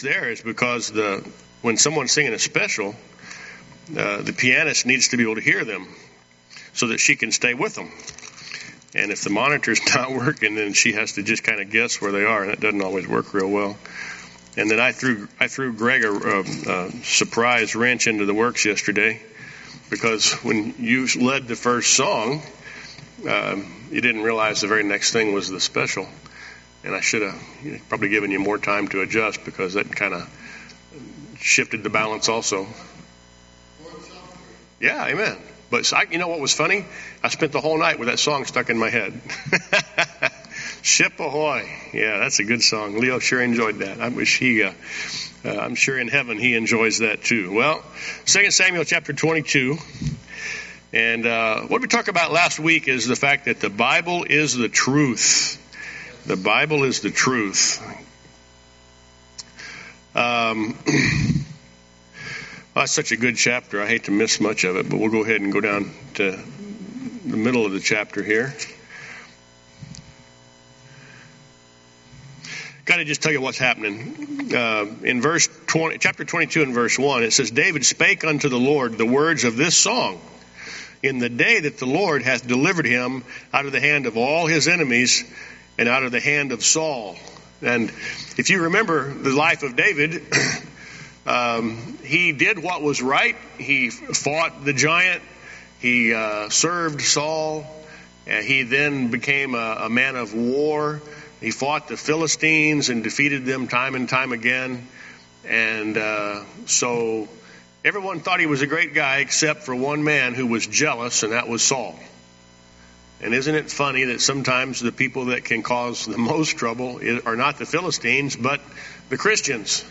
Sunday School Recordings